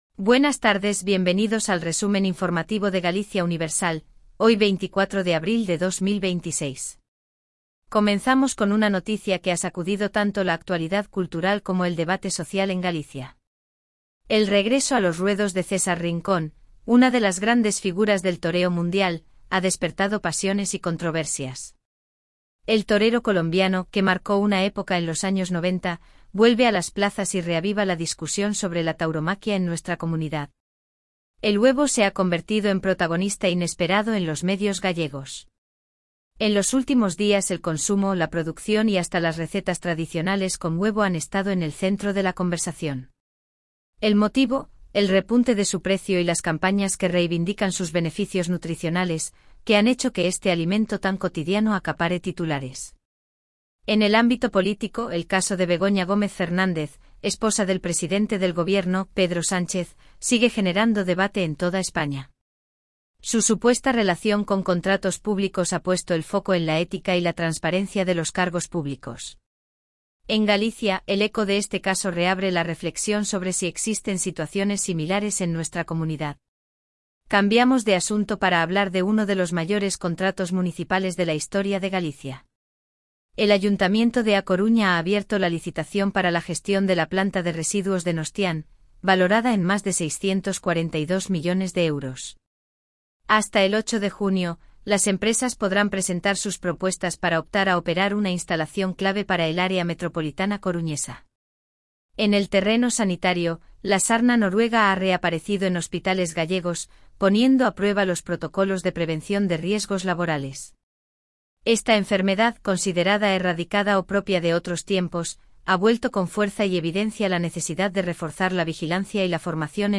🎙 PODCAST DIARIO
Resumen informativo de Galicia Universal